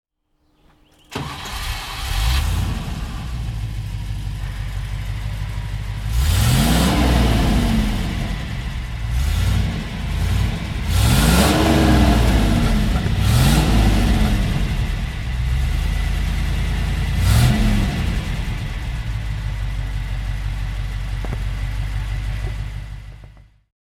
Range Rover 4 Door Monteverdi Design (1982) - Starten und Leerlauf